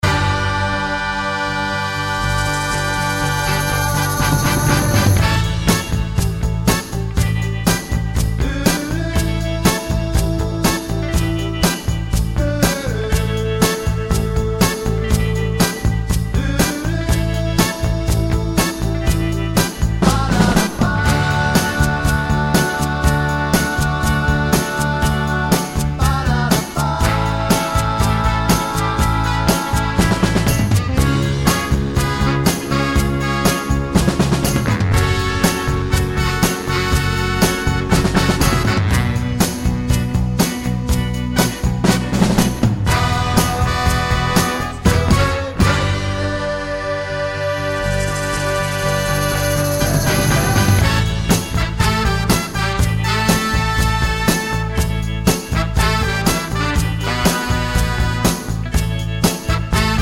no Backing Vocals Soul / Motown 2:49 Buy £1.50